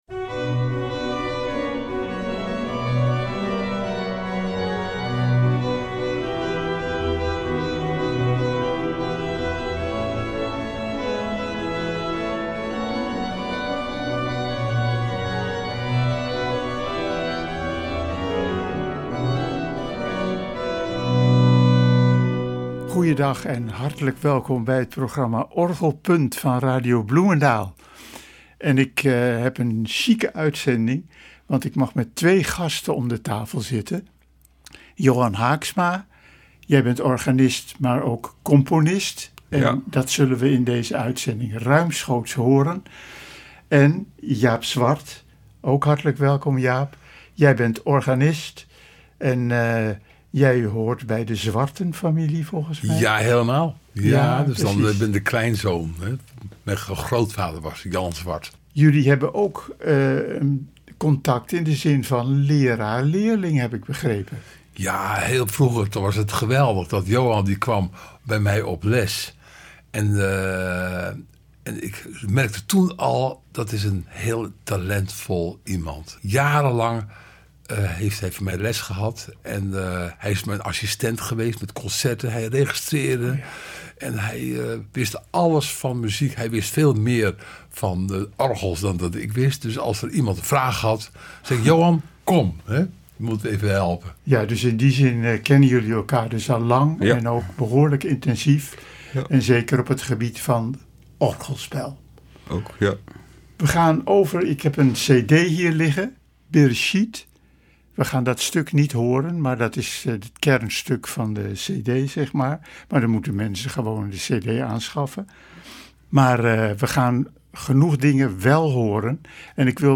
orgelcomposities
op het orgel van de Sint-Lambertuskerk van Hengelo